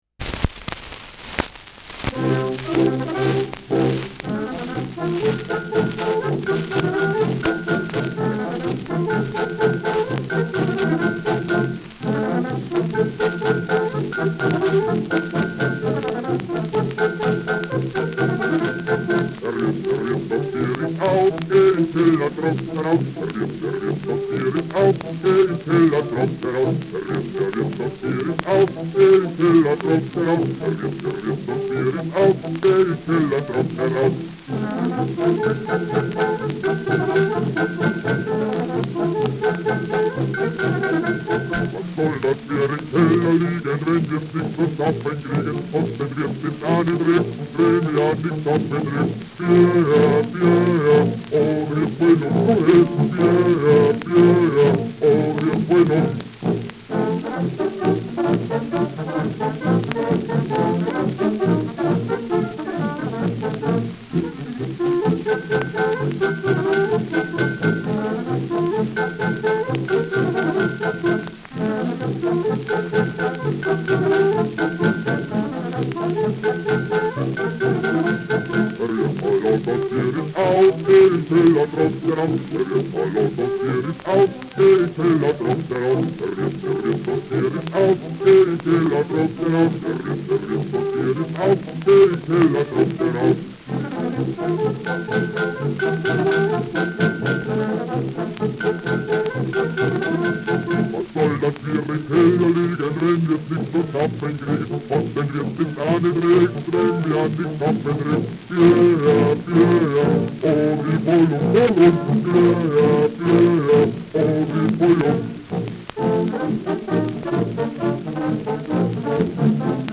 Schellackplatte
Truderinger, Salzburg (Interpretation)